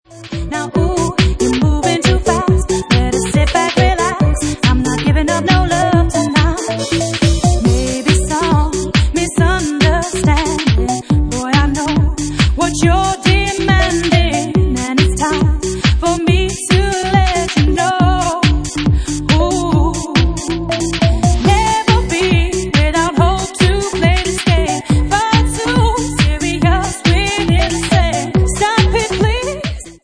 Bassline House at 140 bpm